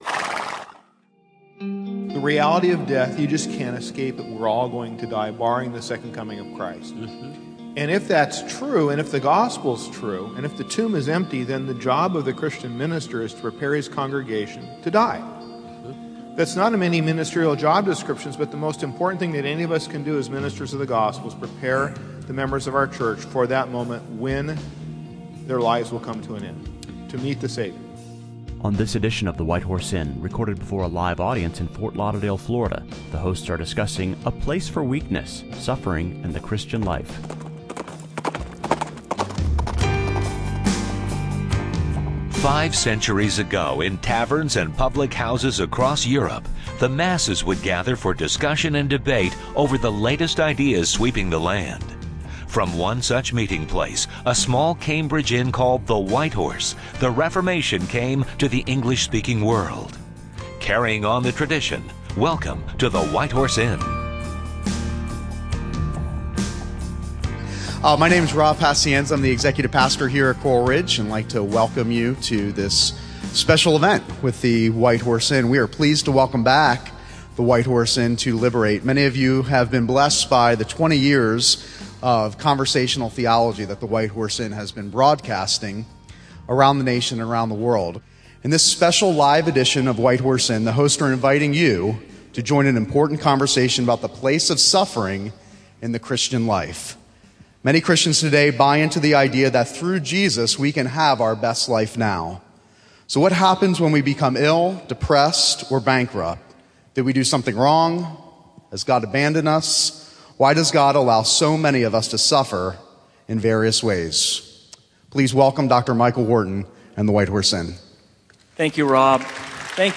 Event(s): Liberate Conference